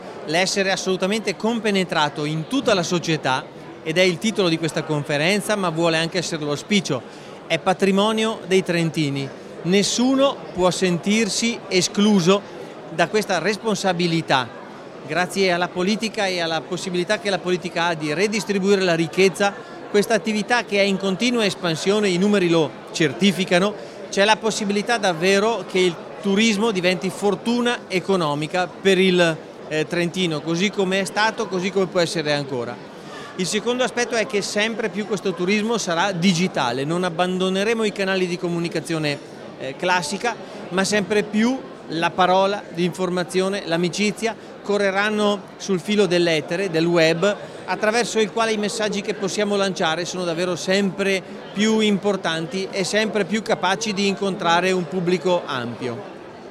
Il presidente della Provincia autonoma ha aperto stamani a Mezzocorona la Conferenza provinciale del comparto turistico